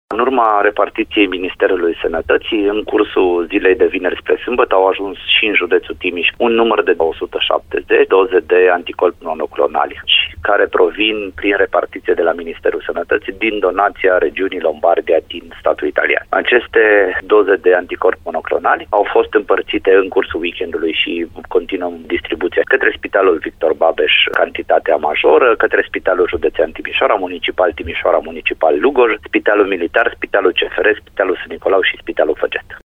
Cantitatea provine, prin repartiție, dintr-o donație sosită din Lombardia, Italia. Directorul DSP Timiș, Flavius Cioca.